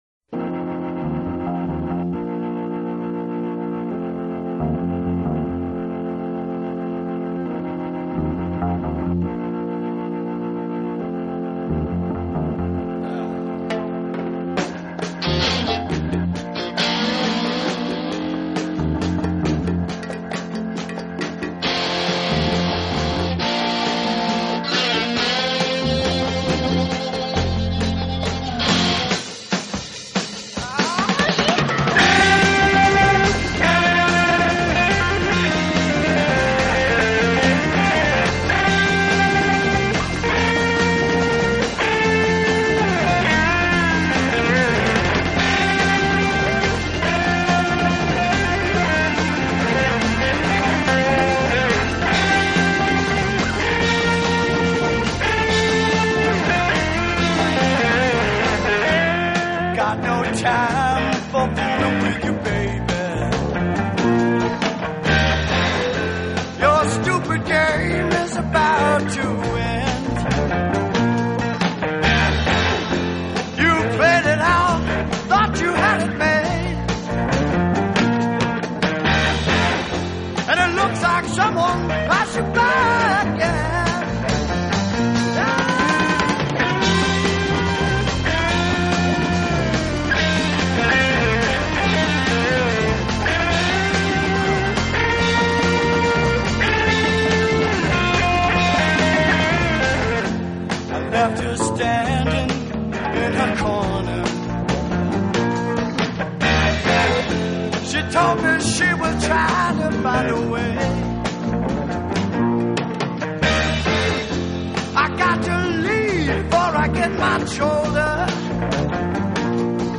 专辑融合了迷幻摇滚、